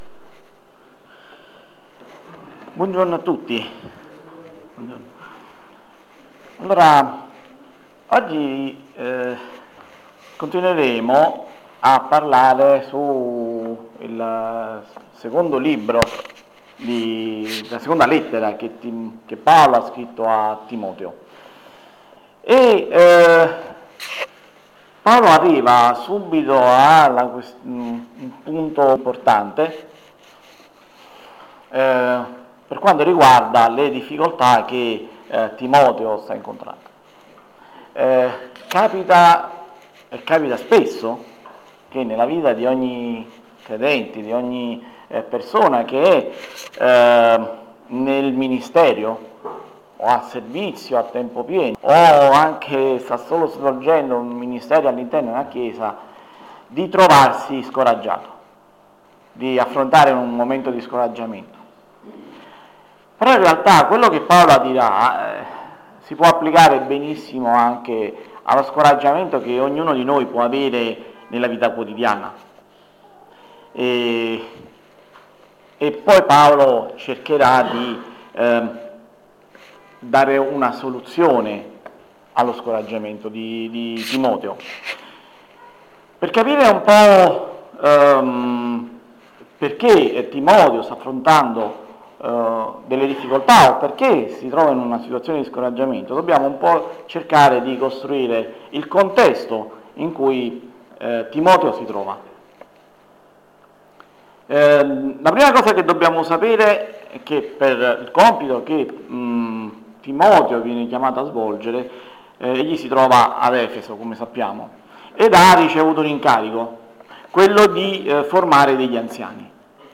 Insegnamenti biblici sul passo di 2 Timoteo 1:6-12.